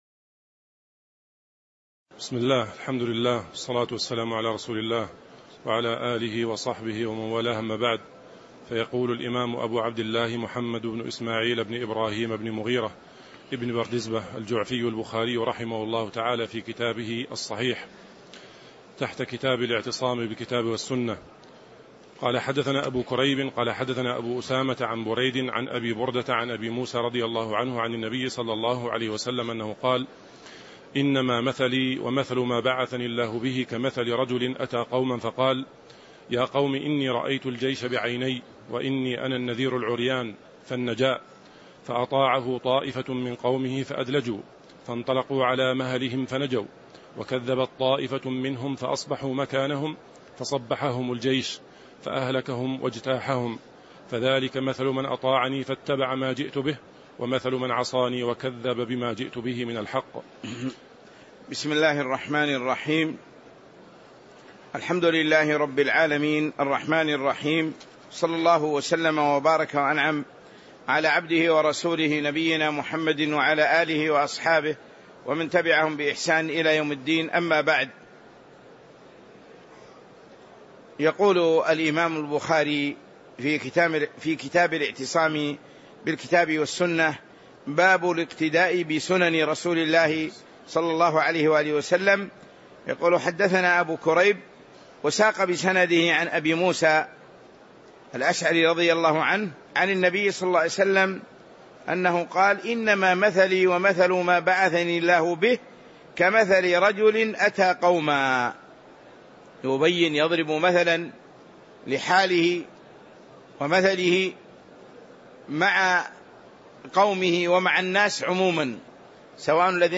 تاريخ النشر ١٥ جمادى الآخرة ١٤٤٦ هـ المكان: المسجد النبوي الشيخ